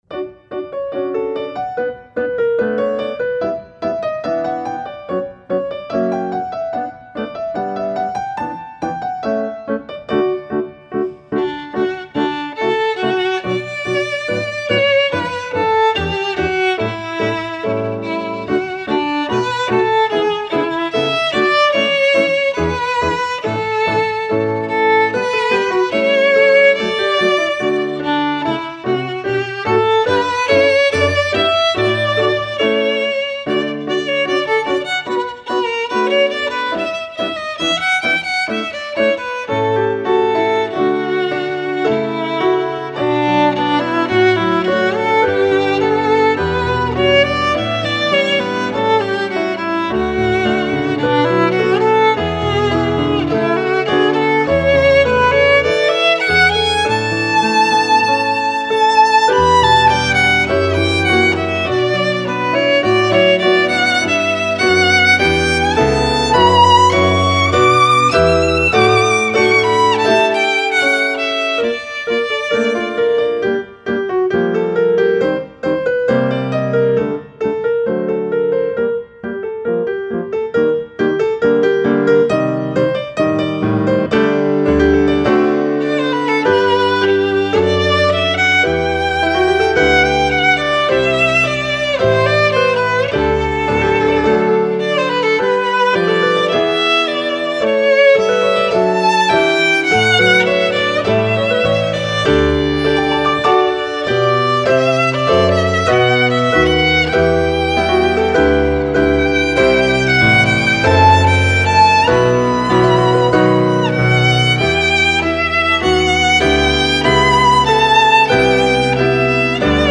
Instrumentation: violin and piano